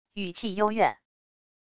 语气幽怨wav音频